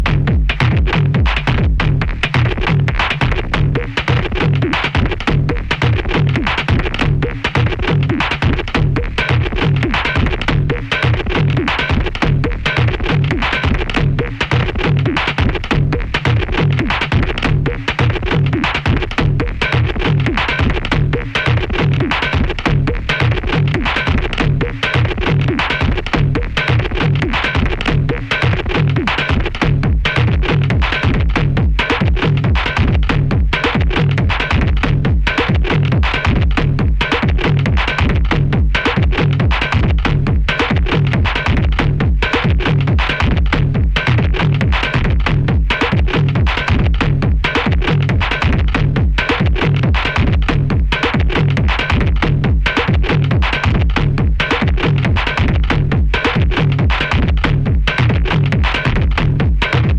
Electronix Ambient